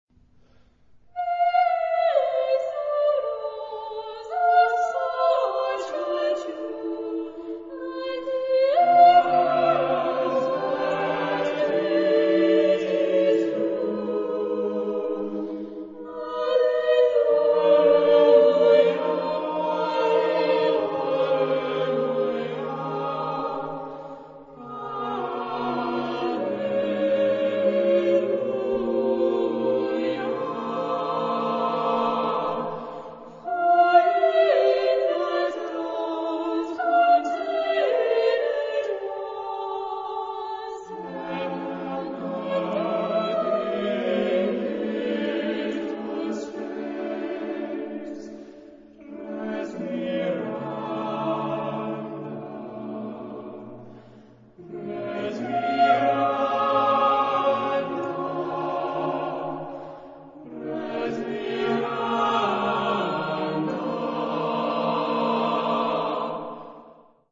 Genre-Style-Form: Sacred ; Choir ; Christmas carol
Type of Choir: SATB (div)  (4 mixed voices )
Tonality: F major